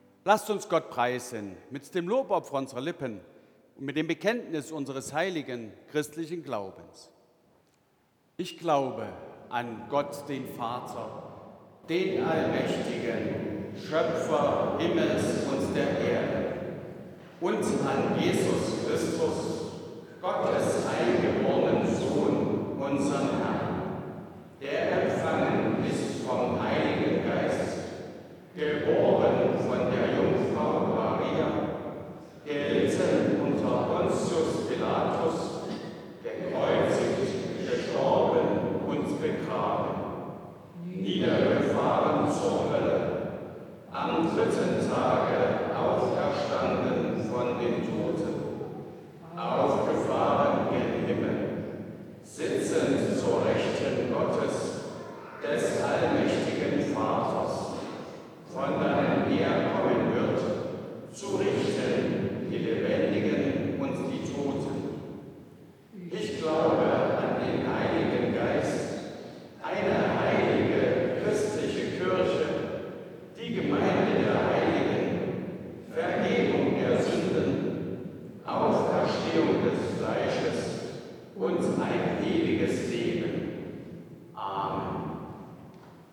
Apostolisches Glaubensbekenntnis Ev.-Luth.
Audiomitschnitt unseres Gottesdienstes vom 3. Sonntag nach Trinitatis 2025.